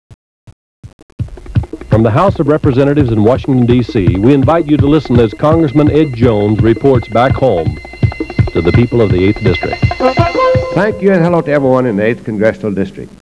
wav file of the voice of Ed Jones